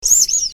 clock02.ogg